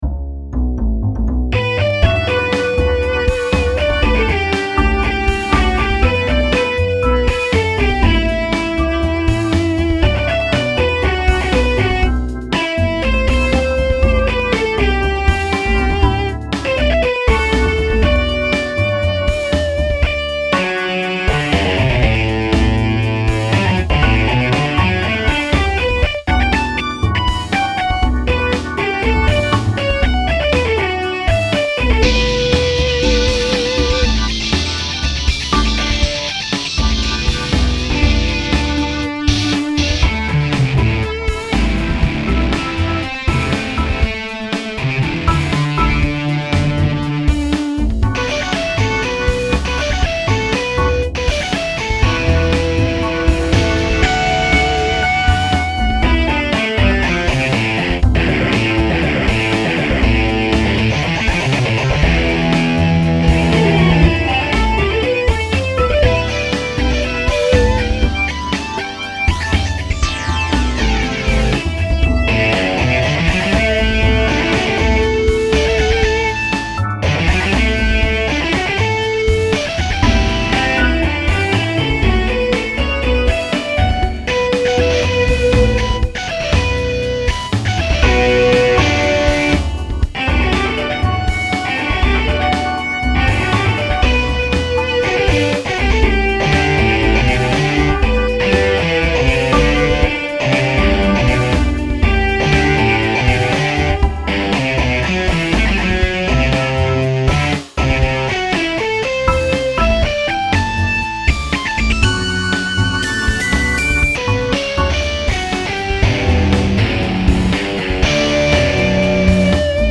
modification du timbre très proche du raga et de la rythmique hindustan. Essai sur logicpro.